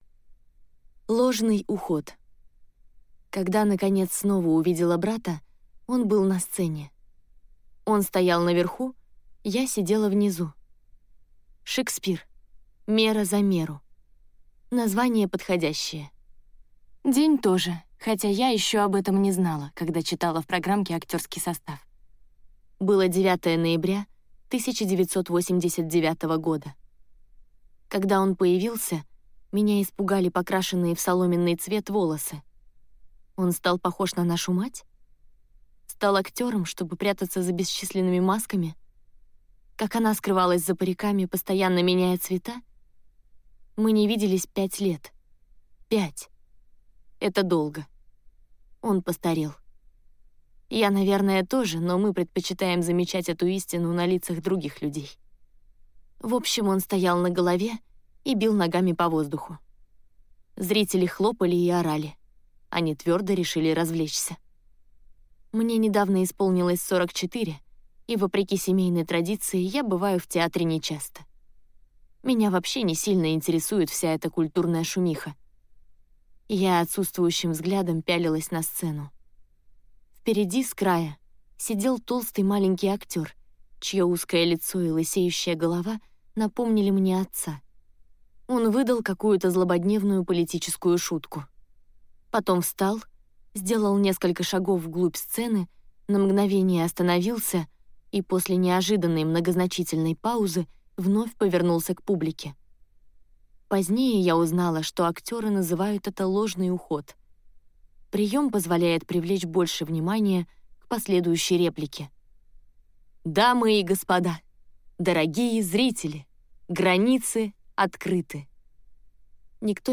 Аудиокнига Моя дорогая Ада | Библиотека аудиокниг